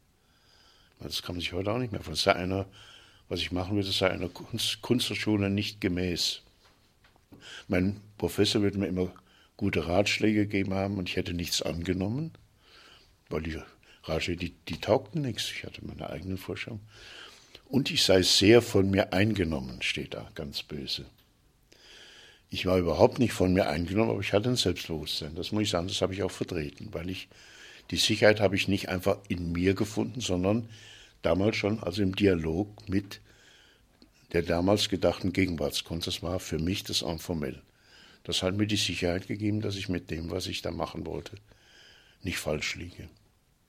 Interview Audioarchiv Kunst: Franz Erhard Walther über das Studium in der Klasse Karl Otto Götz